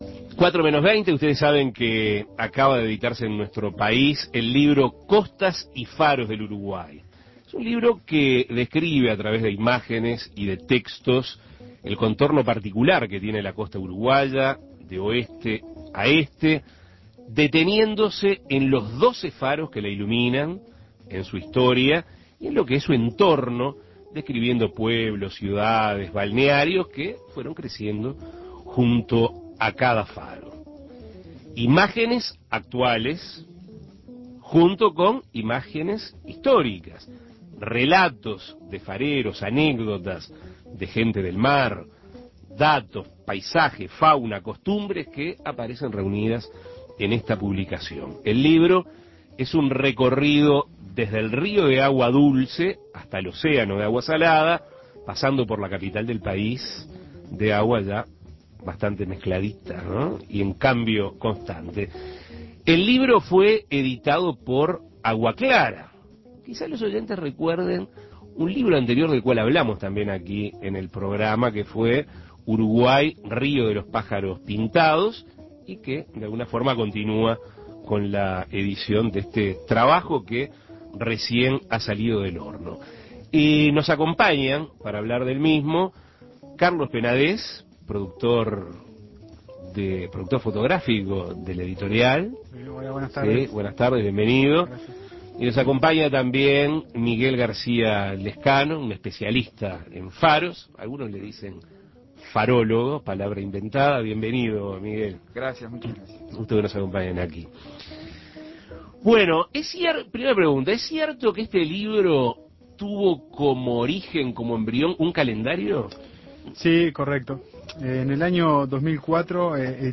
Entrevistas Faros del Uruguay Imprimir A- A A+ Una de las características de la identidad uruguaya son sus costas, y con ellas sus faros.